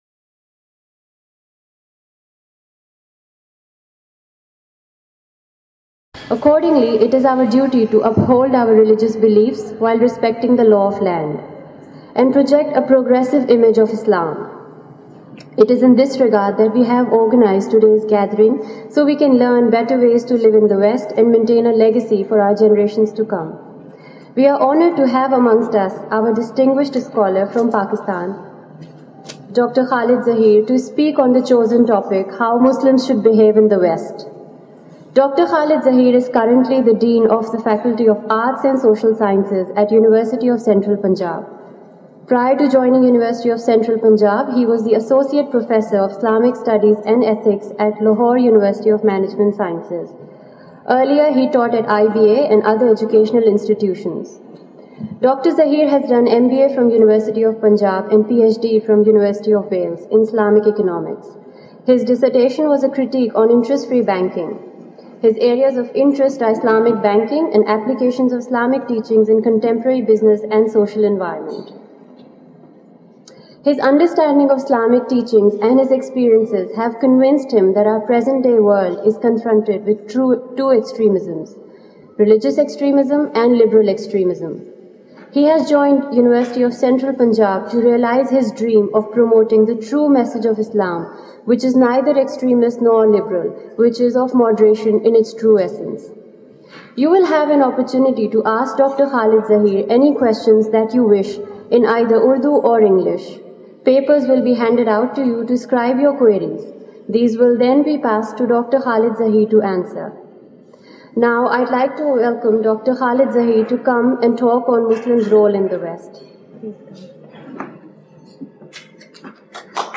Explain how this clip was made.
Venue is Canberra Islamic Centre (CIC), Australia.